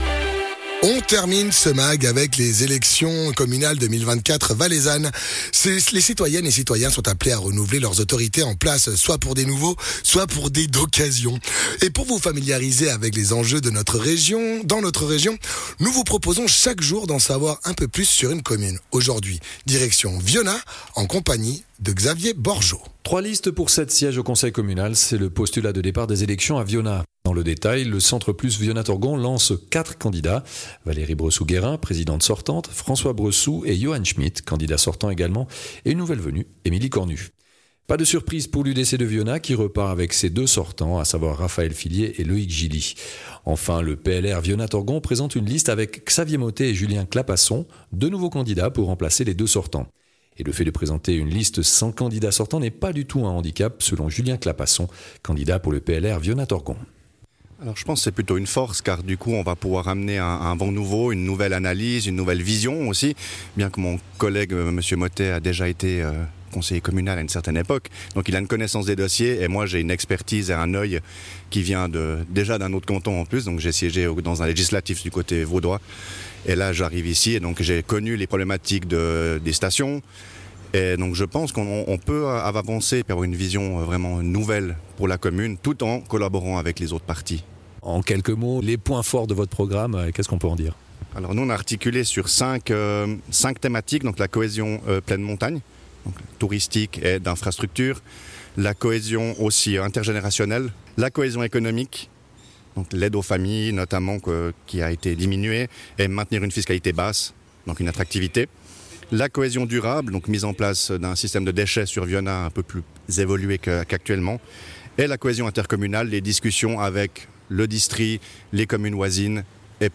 Elections communales VS 24: les entretiens - Vionnaz